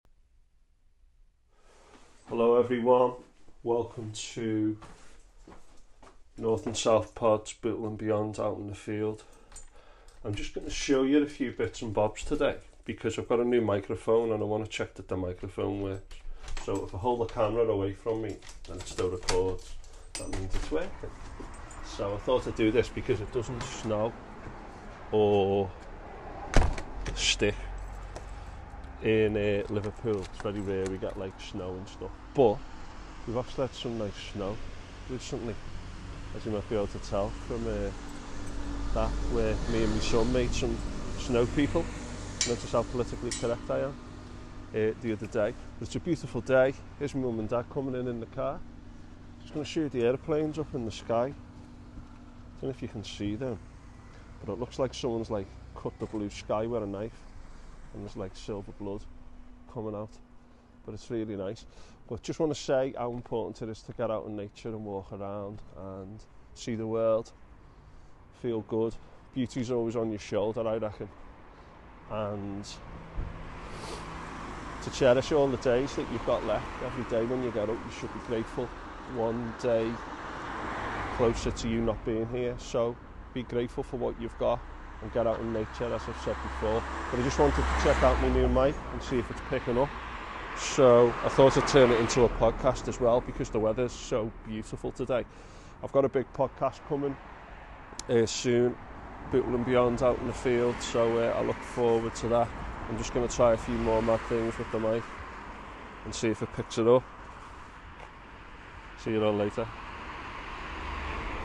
Today I do a mic check on the street in snowy and frosty Bootle under a beautiful blue sky.